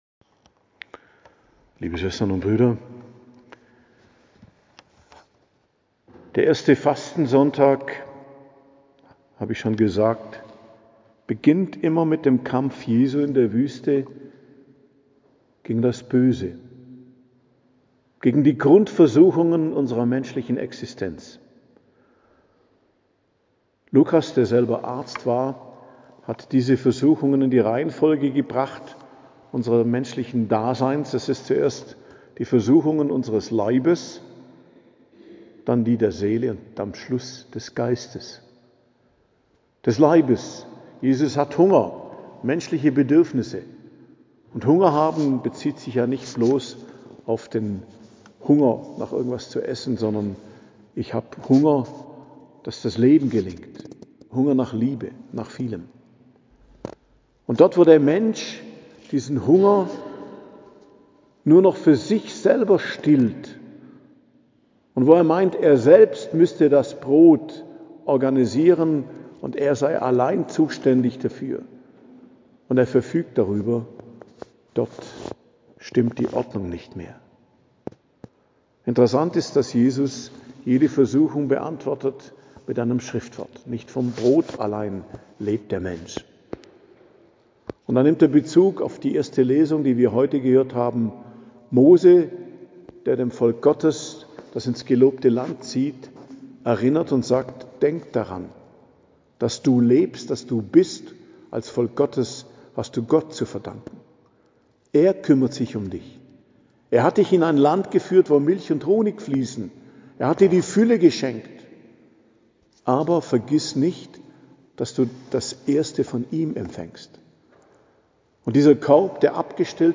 Predigt zum Ersten Fastensonntag, 9.03.2025